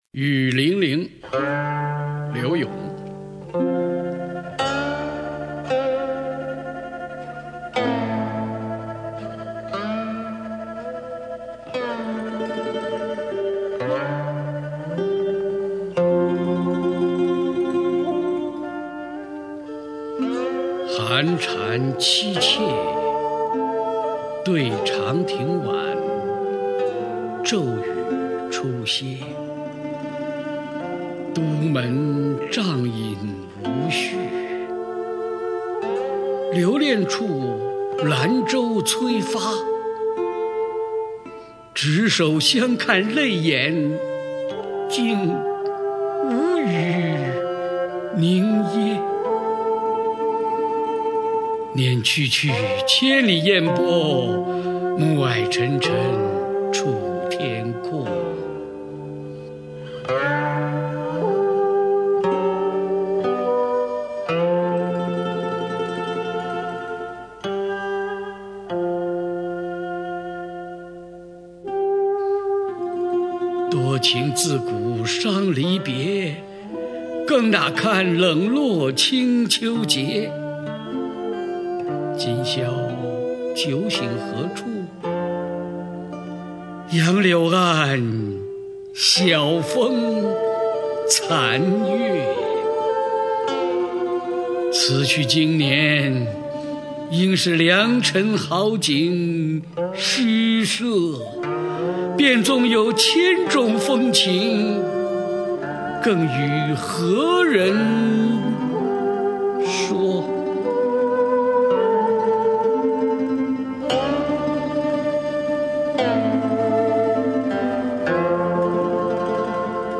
[宋代诗词朗诵]柳永-雨霖铃（男） 古诗词诵读